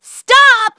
synthetic-wakewords
synthetic-wakewords / stop /ovos-tts-plugin-deepponies_Trixie_en.wav
ovos-tts-plugin-deepponies_Trixie_en.wav